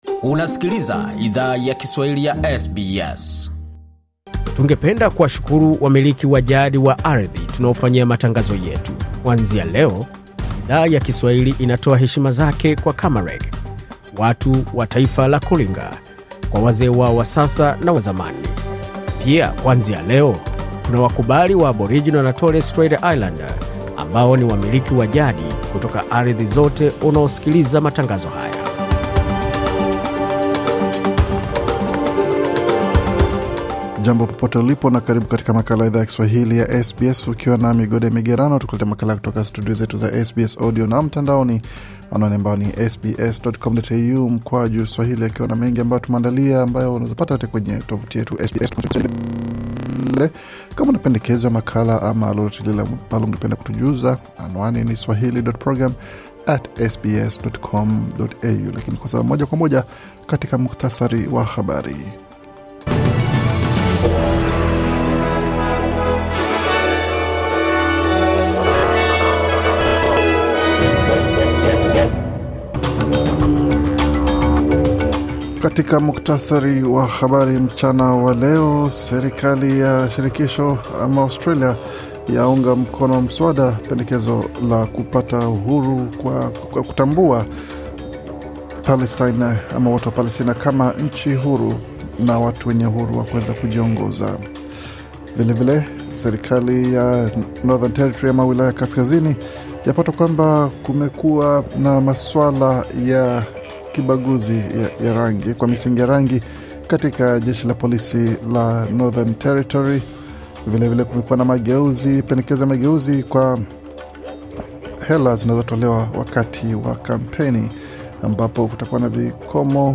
Taarifa ya Habari 15 Novemba 2024